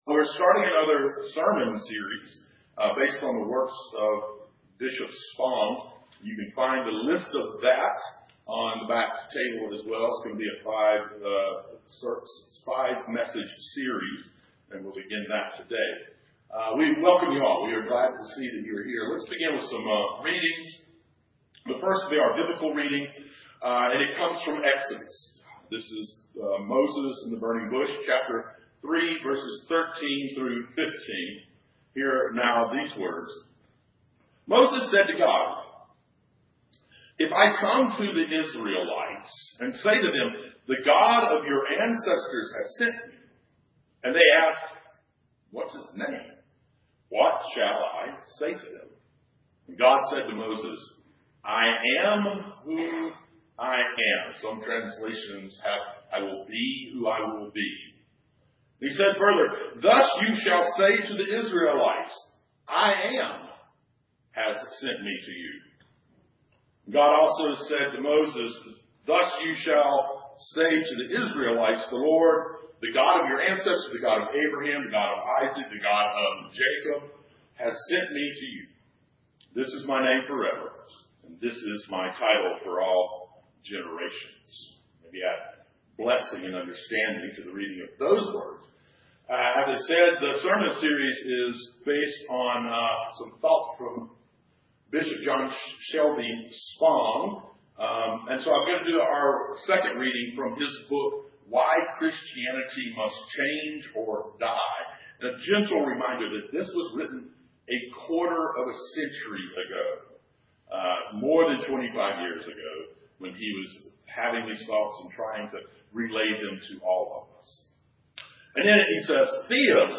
Sermon Series: What if? Faith Beyond the Training Wheels.